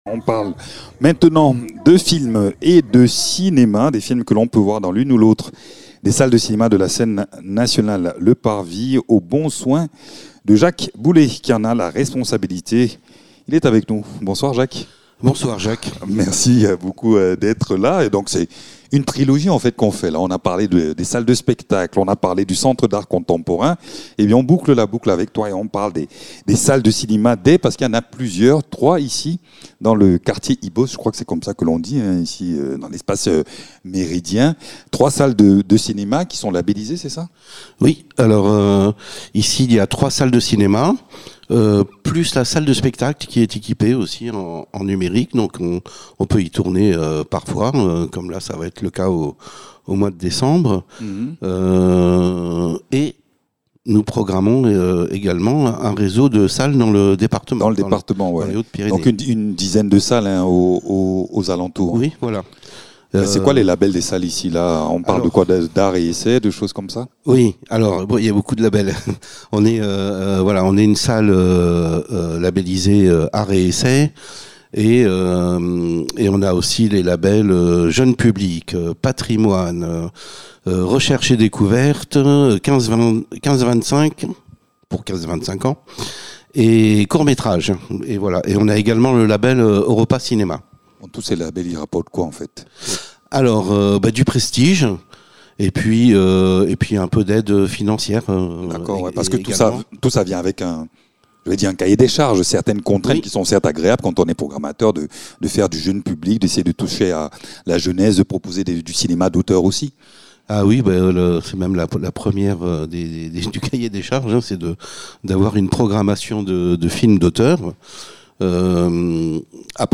Entre films d’auteur, cinéma engagé et séances spéciales, la programmation propose un dialogue vivant avec les spectateurs. Dans cet entretien, découvrez comment le cinéma du Parvis conjugue passion, curiosité et exigence artistique au cœur de Tarbes.